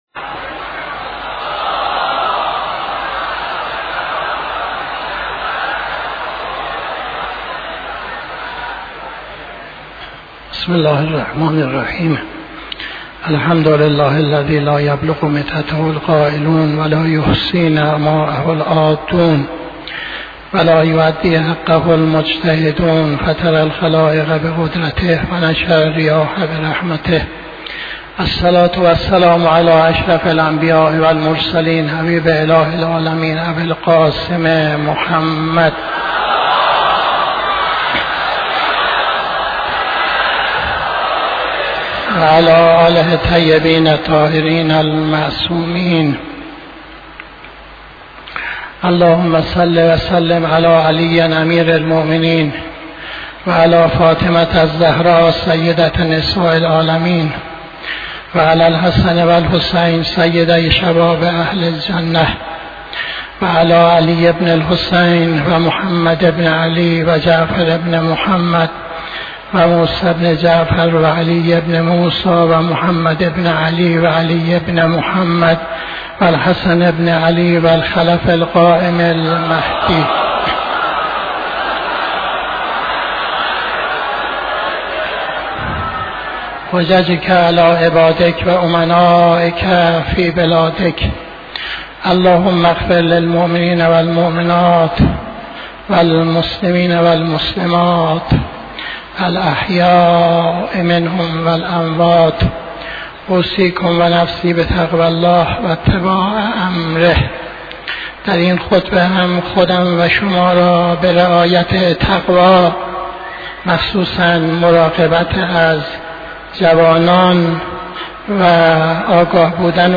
خطبه دوم نماز جمعه 27-04-82